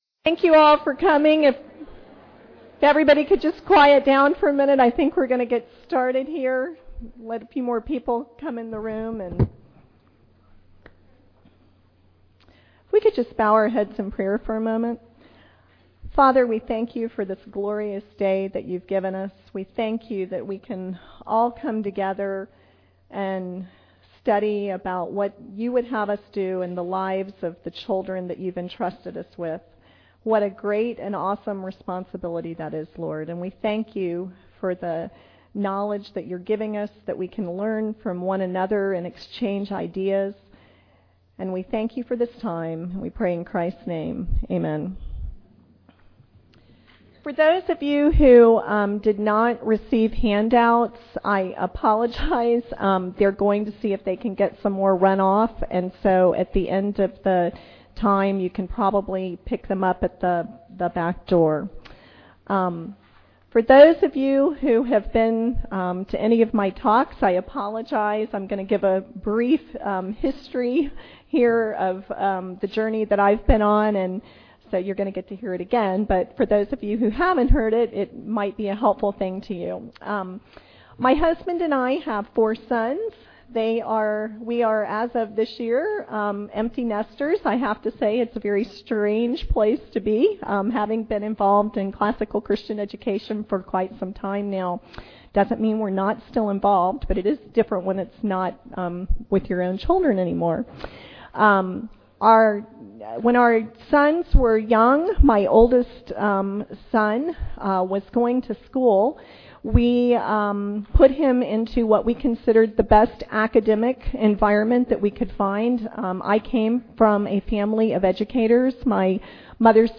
2009 Workshop Talk | 0:53:32 | All Grade Levels, General Classroom
The Association of Classical & Christian Schools presents Repairing the Ruins, the ACCS annual conference, copyright ACCS.